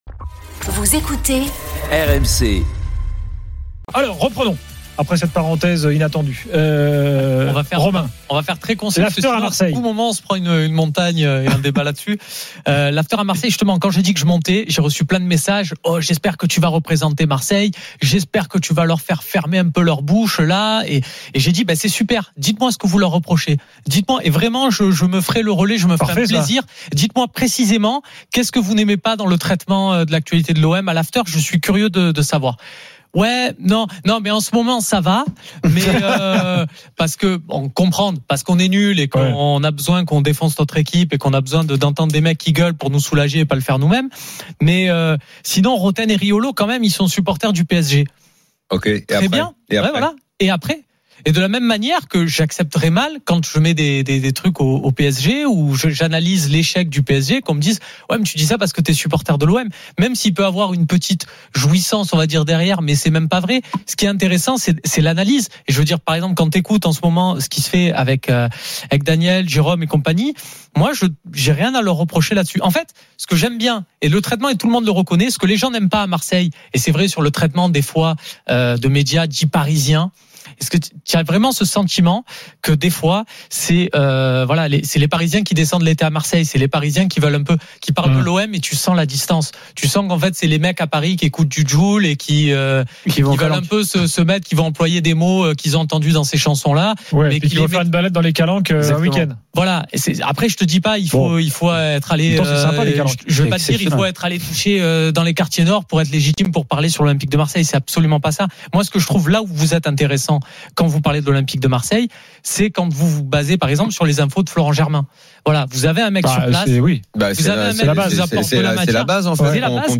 Chaque jour, écoutez le Best-of de l'Afterfoot, sur RMC la radio du Sport !
L'After foot, c'est LE show d'après-match et surtout la référence des fans de football depuis 15 ans !